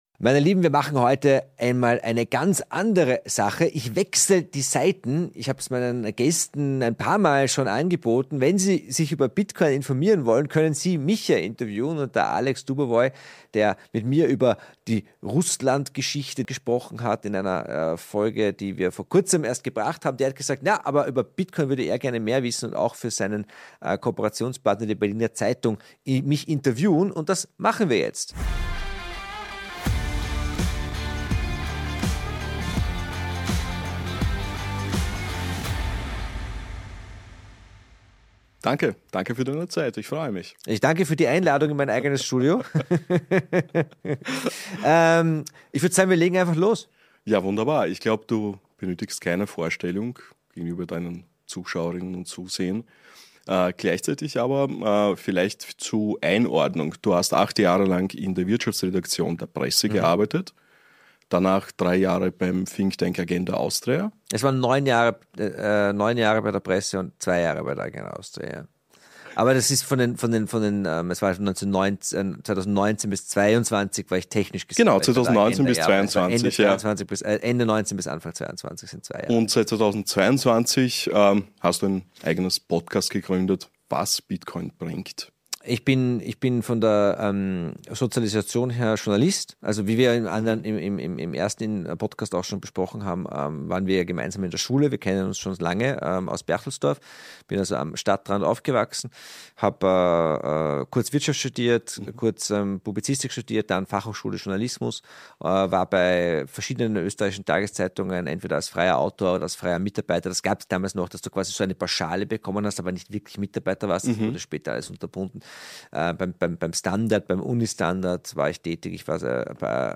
Radikales Potenzial: Mein Bitcoin-Interview für die Berliner Zeitung (ungeschnitten!!) - Was Bitcoin bringt